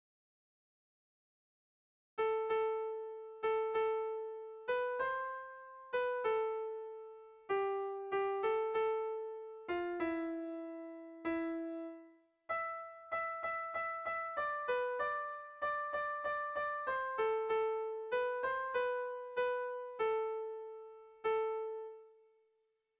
Melodías de bertsos - Ver ficha   Más información sobre esta sección
Irrizkoa
AB